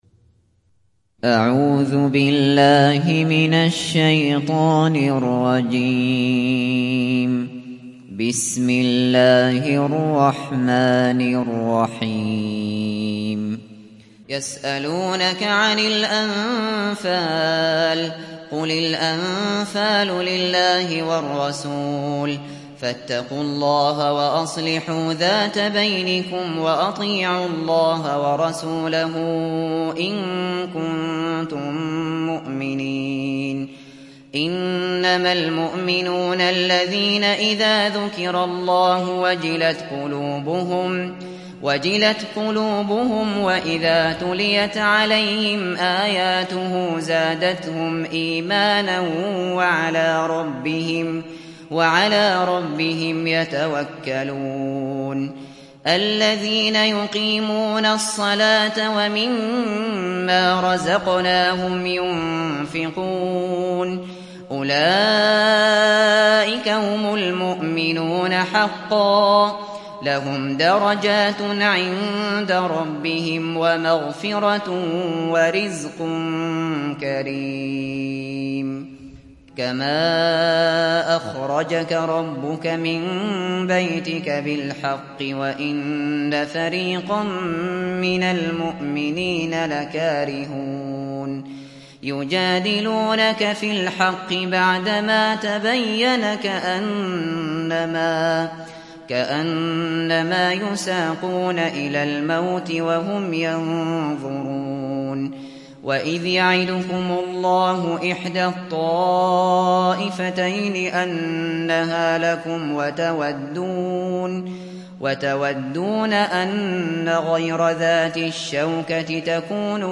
সূরা আল-আনফাল mp3 ডাউনলোড Abu Bakr Al Shatri (উপন্যাস Hafs)